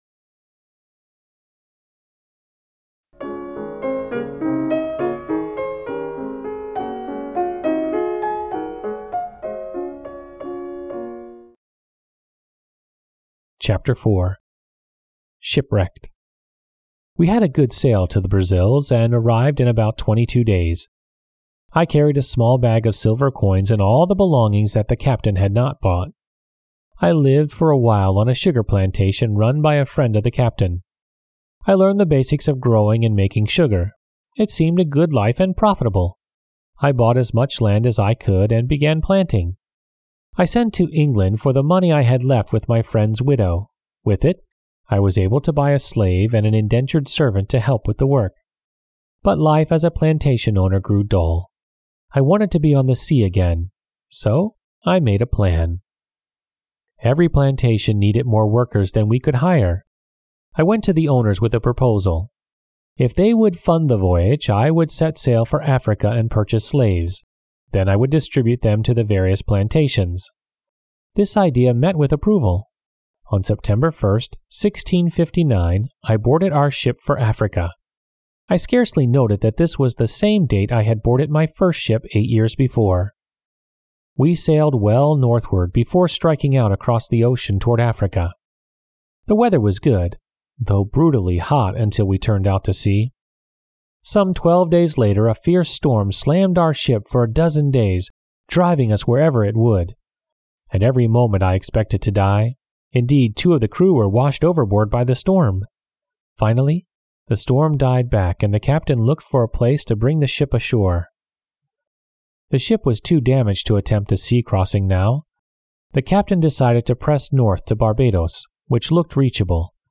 丛书甄选优质中文译本，配以导读、作家作品简介和插图，并聘请资深高考听力卷主播朗读英语有声书。